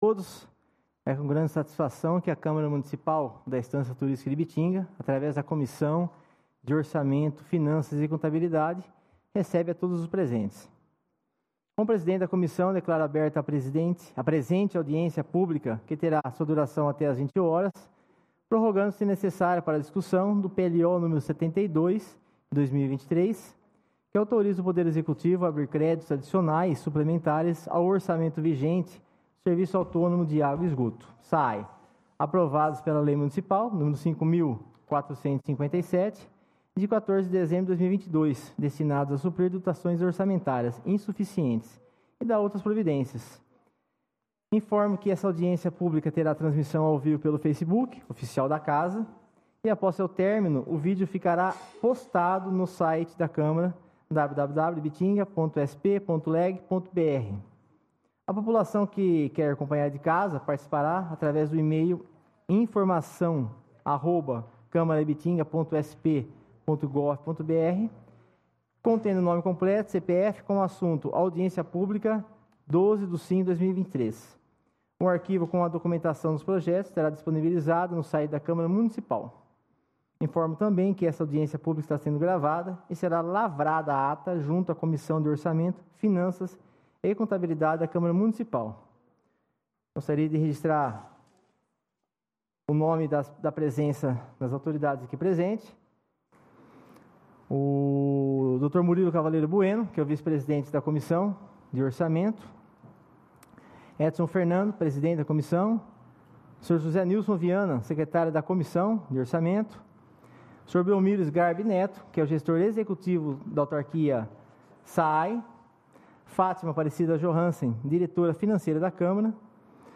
AUDIÊNCIA PÚBLICA REALIZADA PELA COMISSÃO DE ORÇAMENTO, FINANÇAS E CONTABILIDADE